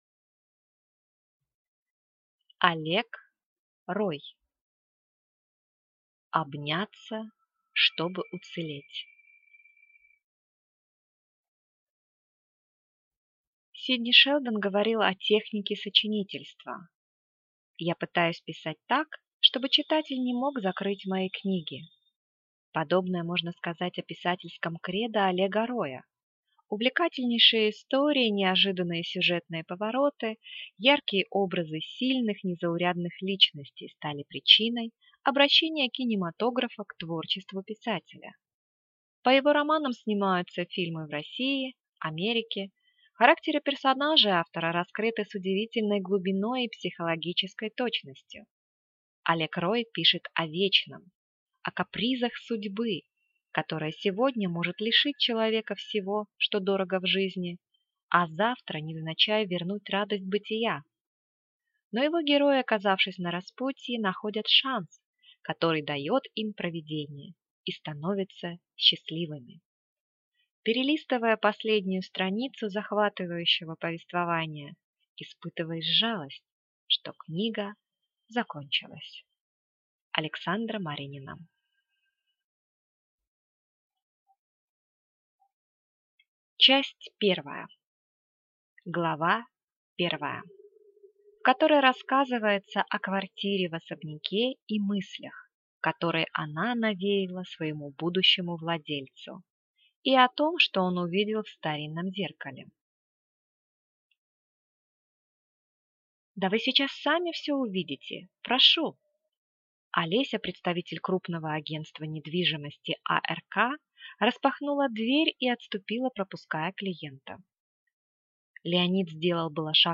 Аудиокнига Обняться, чтобы уцелеть | Библиотека аудиокниг
Прослушать и бесплатно скачать фрагмент аудиокниги